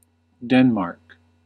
Ääntäminen
Vaihtoehtoiset kirjoitusmuodot (vanhentunut) Denmarke Synonyymit Kingdom of Denmark Ääntäminen US UK : IPA : /ˈdɛn.mɑːk/ US : IPA : /ˈdɛn.mɑɹk/ Lyhenteet ja supistumat (laki) Den. Haettu sana löytyi näillä lähdekielillä: englanti Käännös Ääninäyte Erisnimet 1.